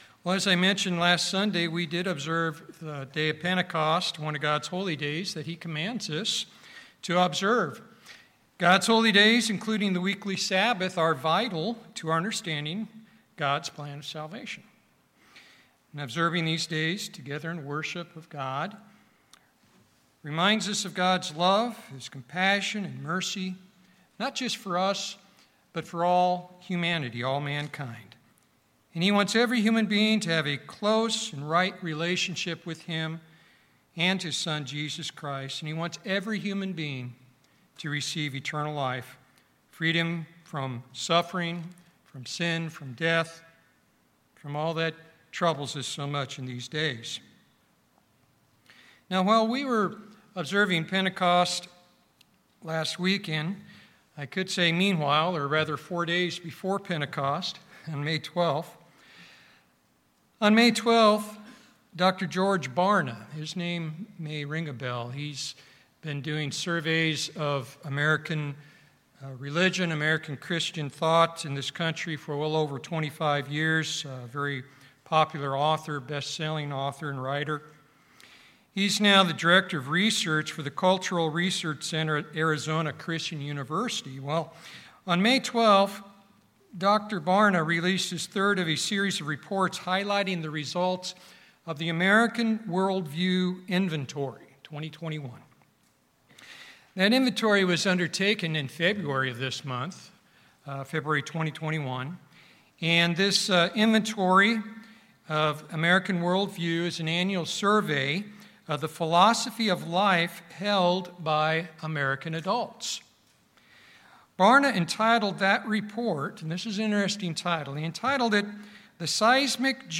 In this sermon we will learn of the latest findings about a significant shift in the worldview of Americans to what has been called a "fake Christianity" and address three keys to avoiding the terrible deception and spiritual pitfalls espoused by this worldview called Moralistic Therapeutic Deism.